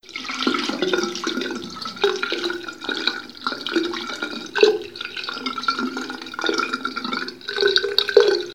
Lavabo con agua corriendo
Me gusta Descripción Grabación sonora en la que se aprecia el sonido del agua corriendo en el lavabo mientras se evacúa por la tubería. Sonidos cotidianos